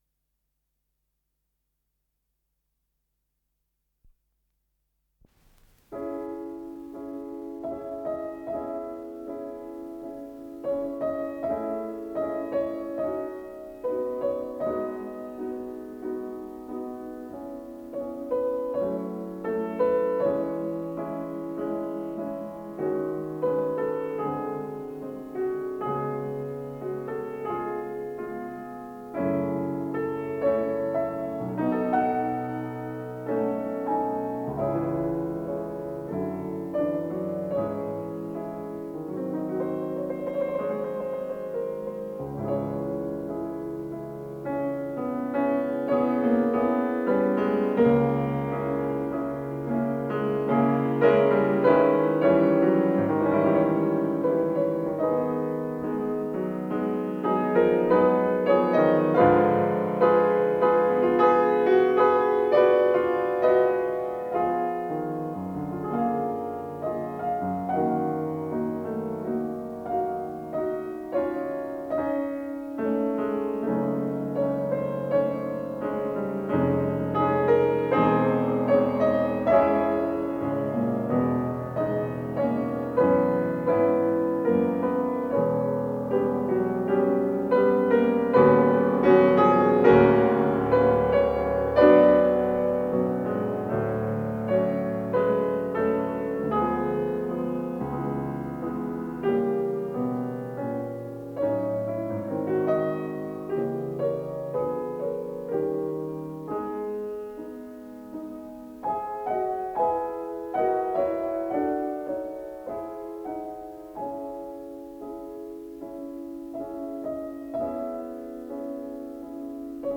с профессиональной магнитной ленты
Авторы версииЕвгений Светланов - обработка
ИсполнителиЕвгений Светланов - фортепиано
ВариантДубль моно